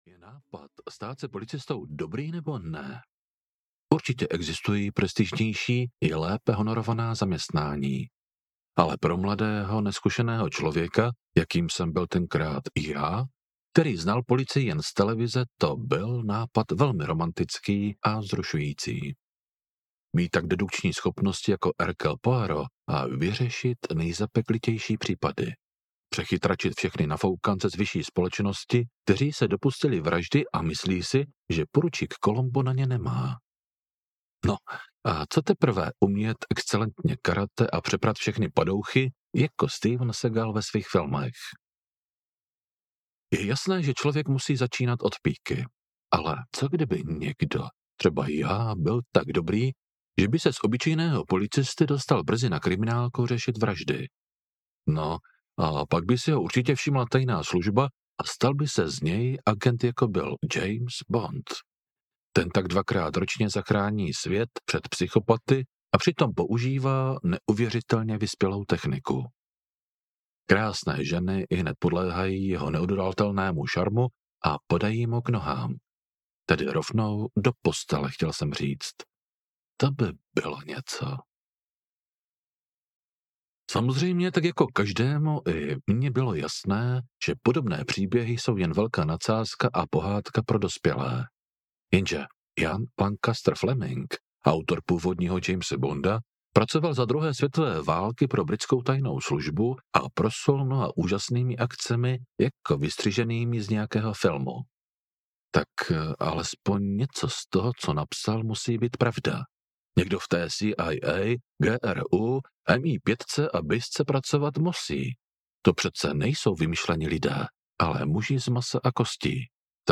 Ach jo, to by se policistovi stávat nemělo audiokniha
Ukázka z knihy